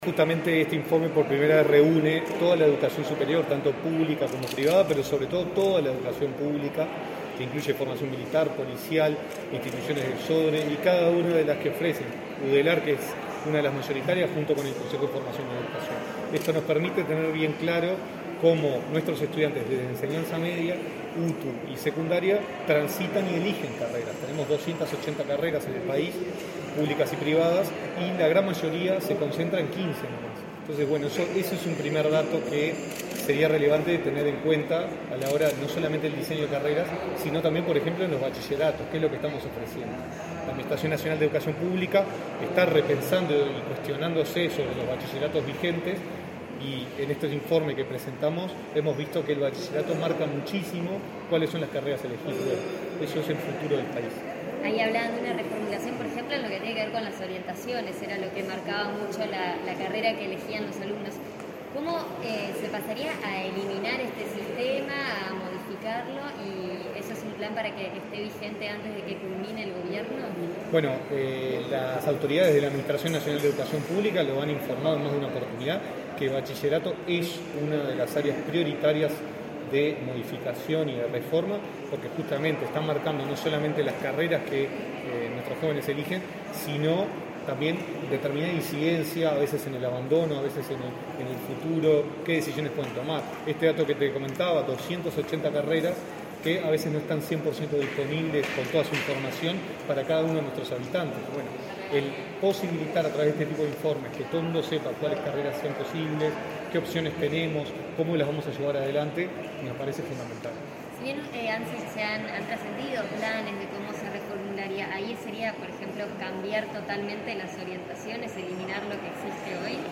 Declaraciones a la prensa del director de Educación, Gonzalo Baroni
El director de Educación, Gonzalo Baroni, dialogó con la prensa acerca de la presentación de la caracterización del ingreso a carreras de educación